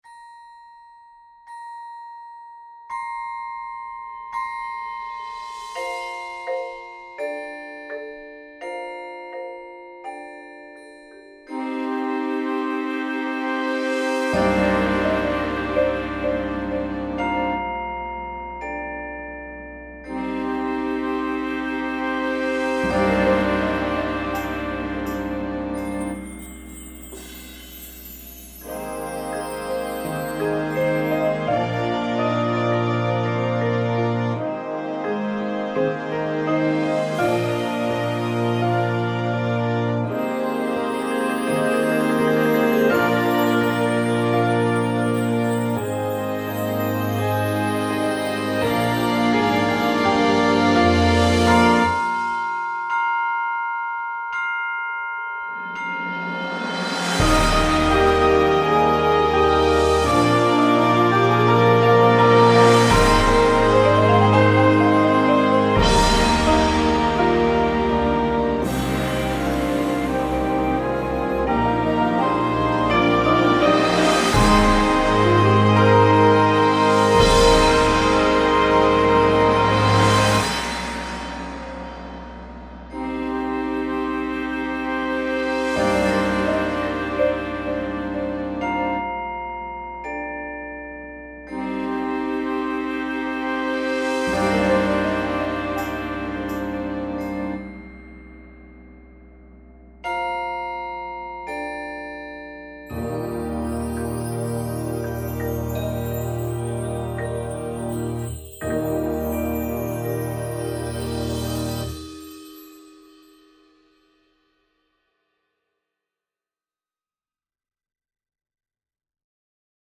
Instrumentation:
• Flute
• Alto Saxophone
• Trumpet 1, 2
• Trombone 1, 2
• Tuba
• Snare Drum
• Synthesizer
• Marimba 1
• Vibraphone 1